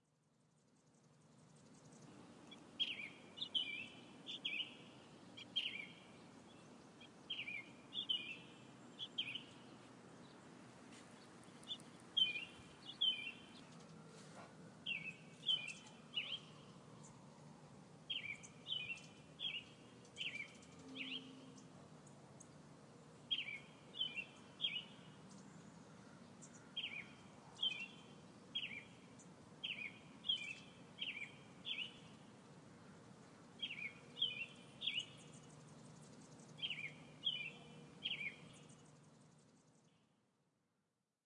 鸟叫声森林
描述：在北威尔士的一个森林里，晚春，鸟儿在叫。带电容式麦克风的迷你盘。
Tag: 树林 林地 birdcalls 森林 鸟鸣 birdcalls